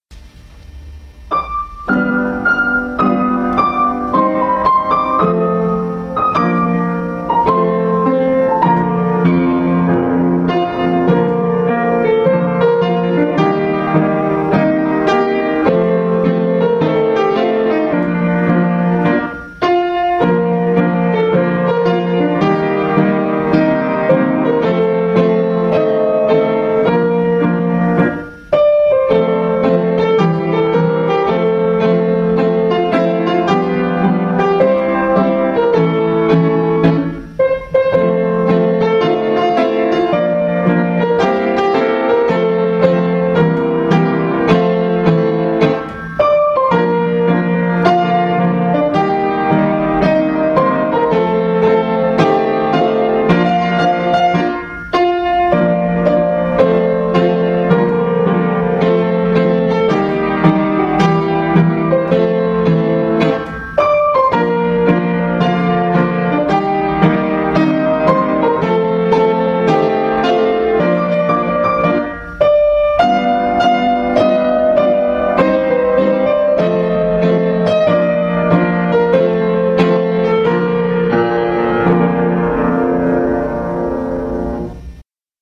Добрый вечер,волшебники!Сотворите,пожалуйста, чудо..Мне нужно добавить сюда 2 куплета(чтоб было всего 3) и,если можно - чтоб припев звучал по 1 разу, а не по 2,как здесь.